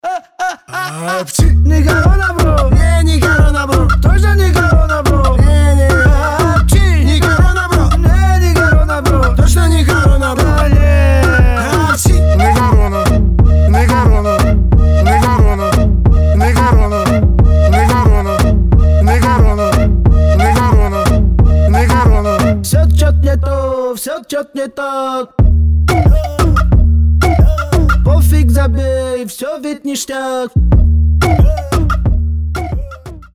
ритмичные
веселые
заводные
качающие
Moombahton